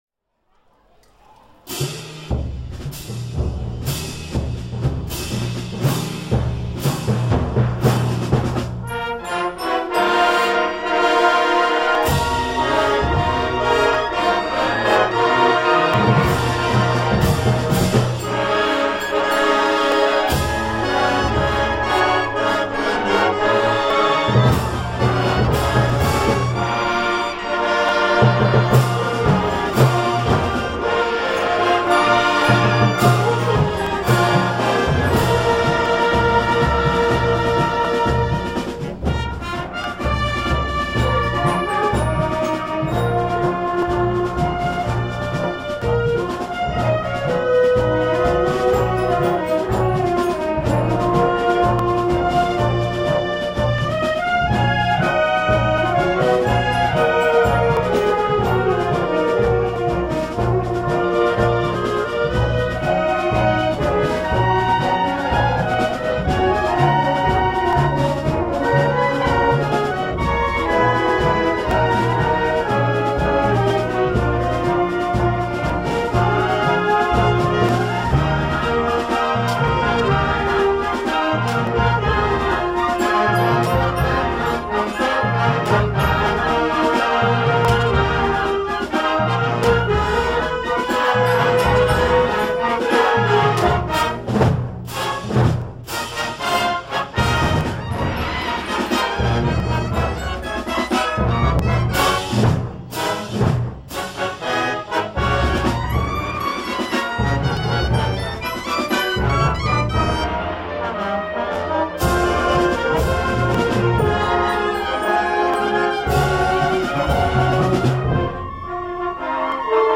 marcha mora